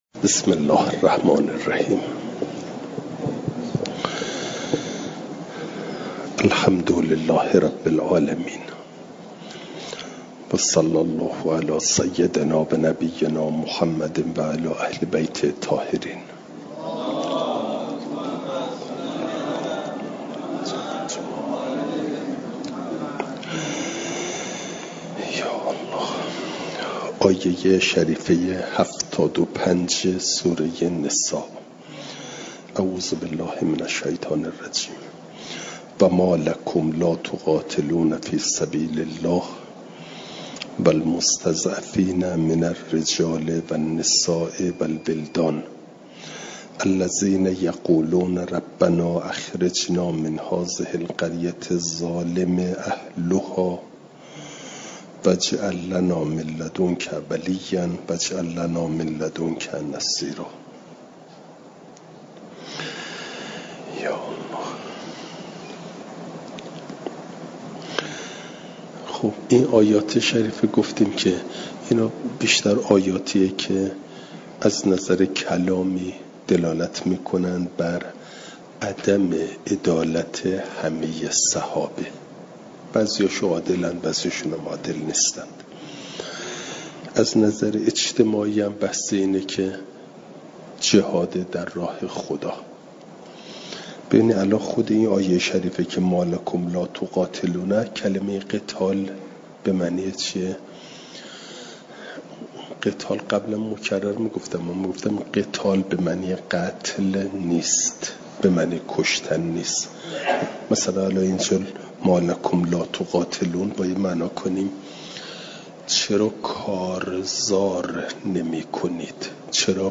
جلسه سیصد و هفتاد و پنجم درس تفسیر مجمع البیان